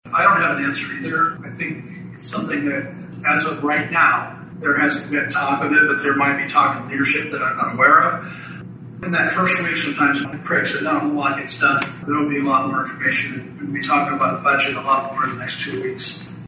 The first Carroll Chamber of Commerce Legislative Forum on Saturday was filled with questions revolving around funding.